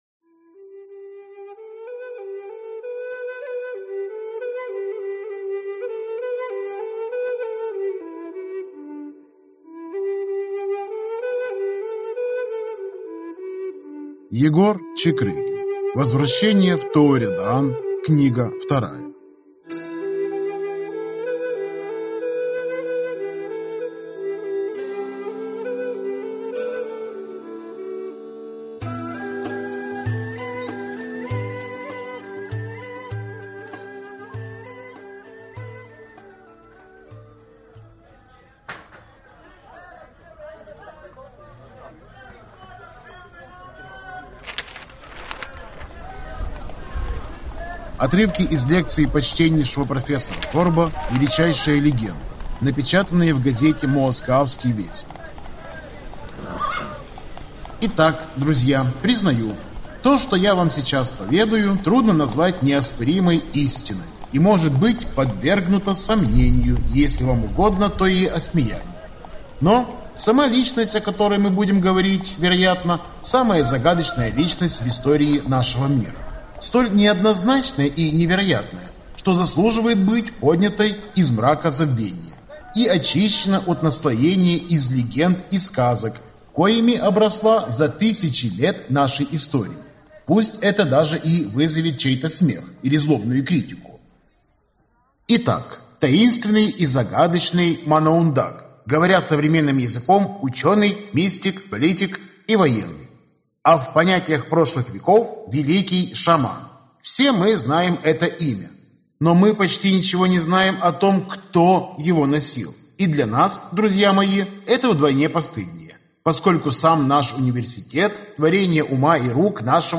Аудиокнига Возвращение в Тооредаан. Книга 2 | Библиотека аудиокниг